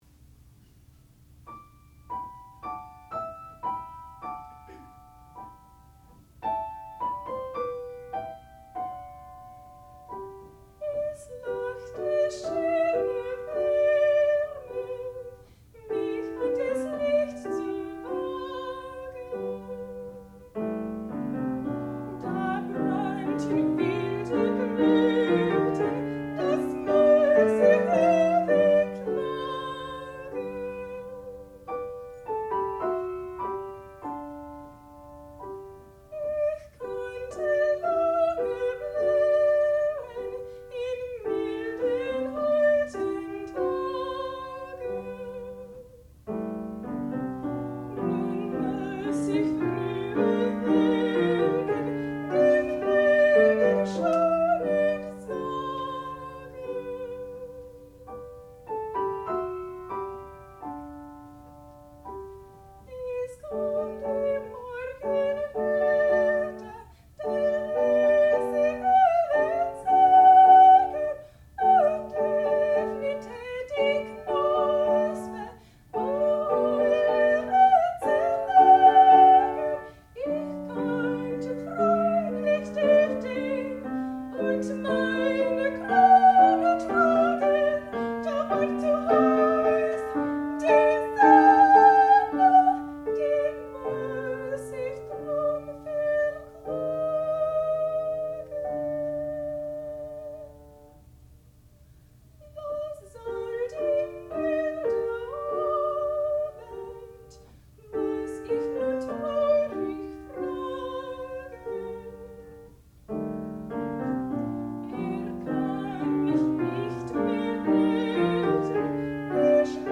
sound recording-musical
classical music
piano
soprano
Student Recital